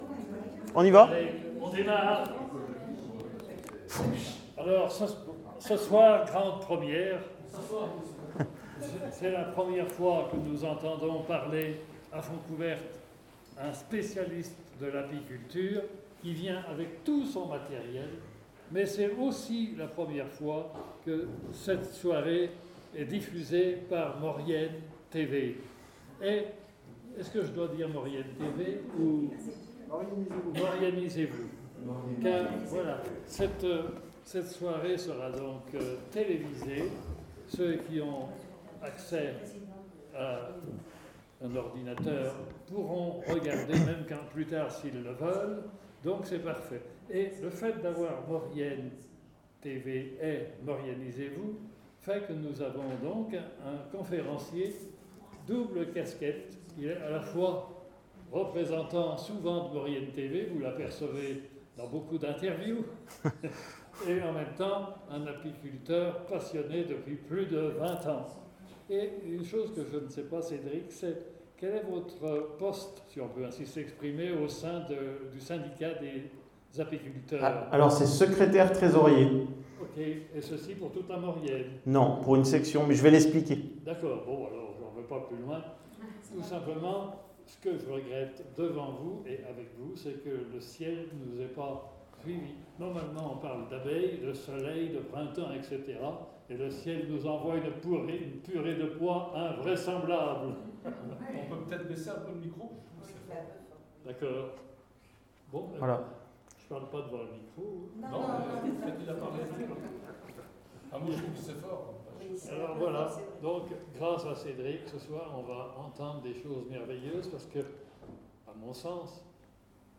hors-serie-conference🐝-la-passion-de-lapiculture-🍯.mp3